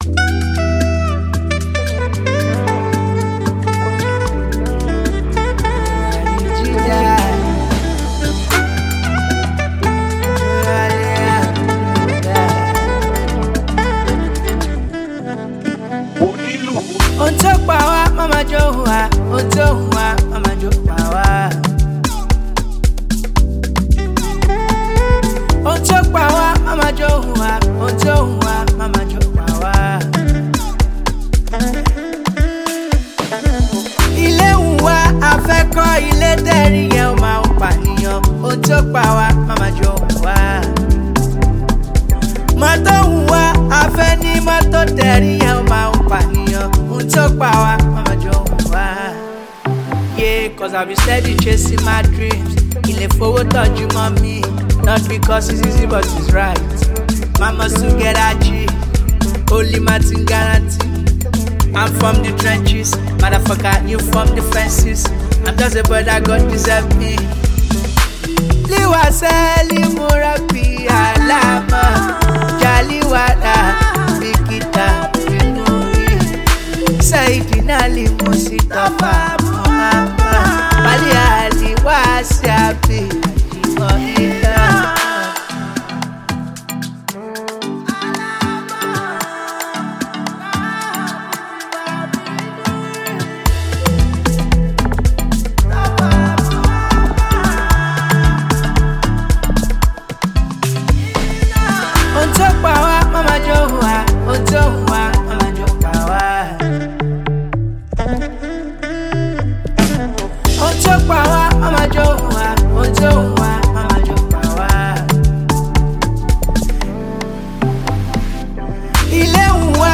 Highly talented Nigerian singer and songwriter